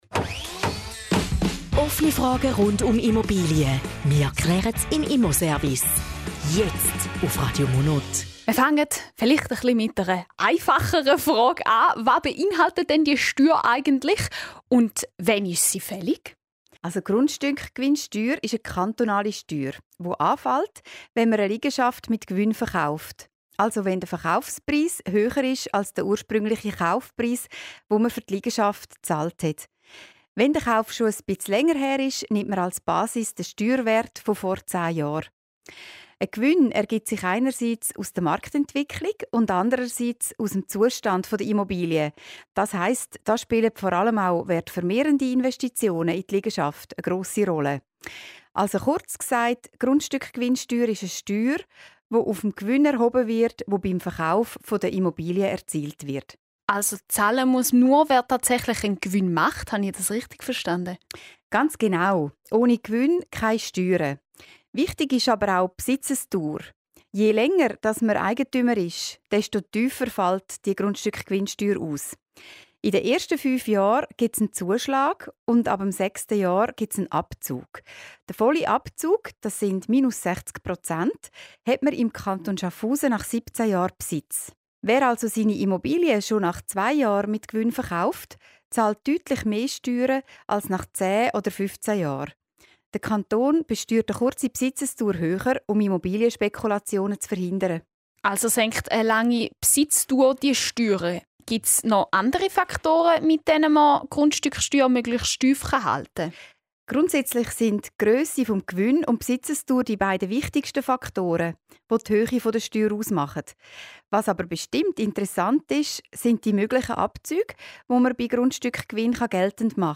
Zusammenfassung des Interviews zum Thema "Grundstückgewinnsteuer":